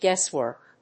/ˈgɛˌswɝk(米国英語), ˈgeˌswɜ:k(英国英語)/
アクセント・音節guéss・wòrk